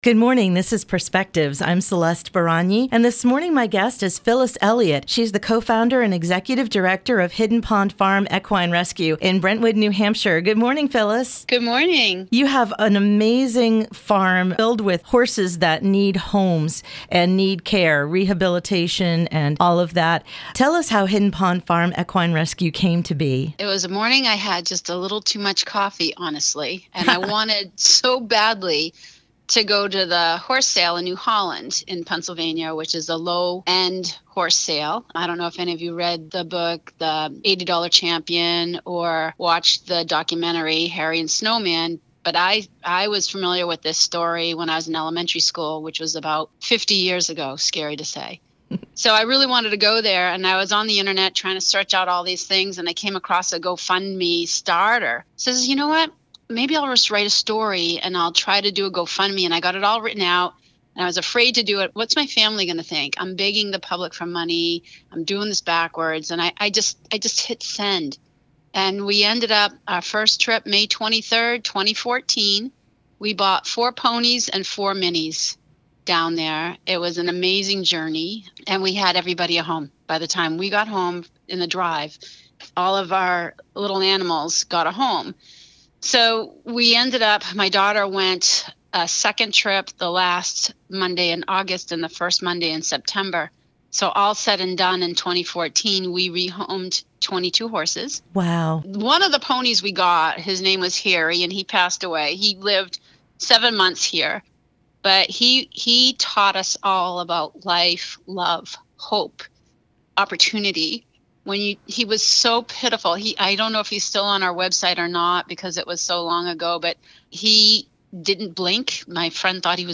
An excellent interview